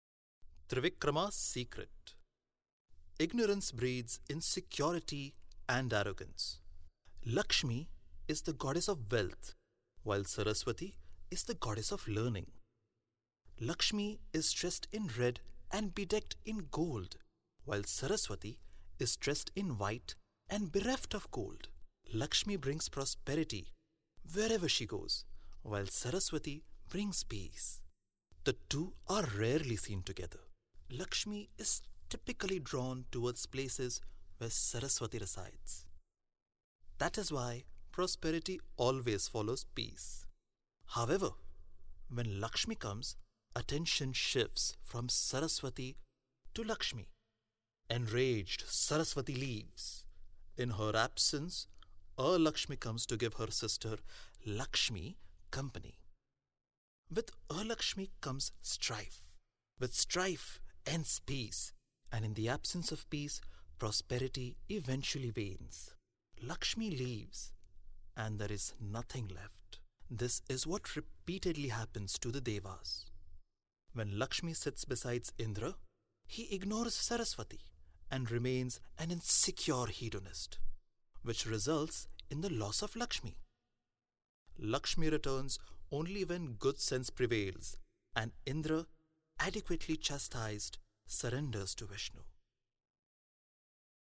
Has a young voice which suits narrative style.
Sprechprobe: eLearning (Muttersprache):